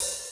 OpenHH Funk 1.wav